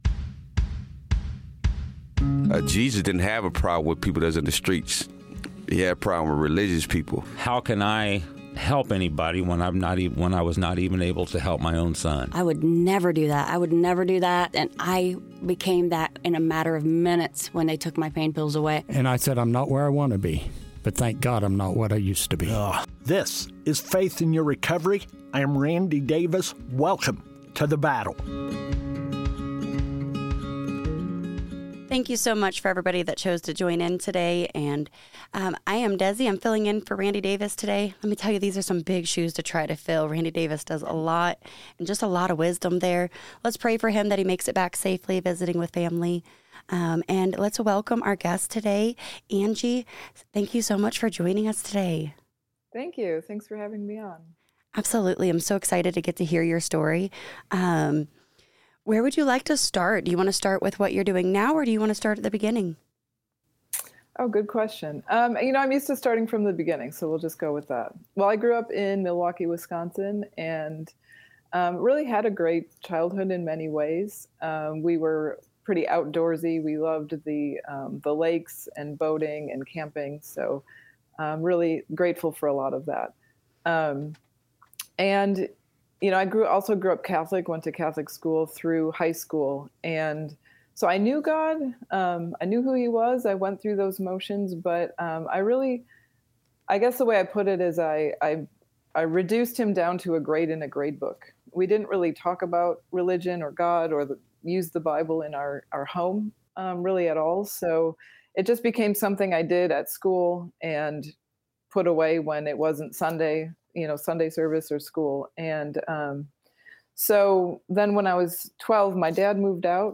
Join us as we interview inmates about their experiences with addiction and recovery. This episode was produced from inside the Jay County Security Center.